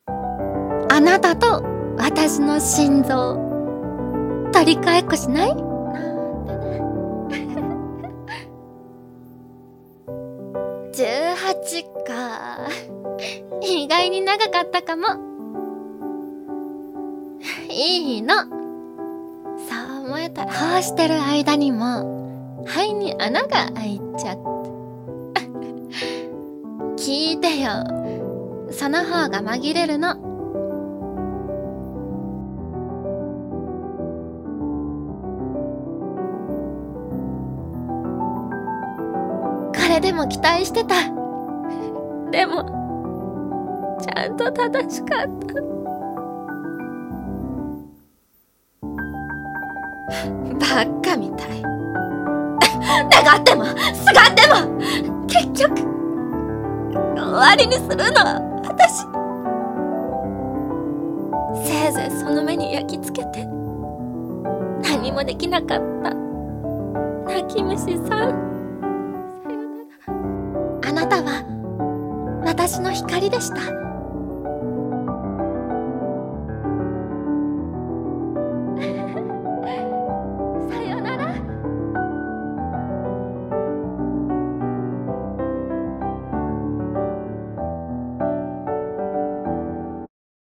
声劇【見紛う桜に君は埋まっている】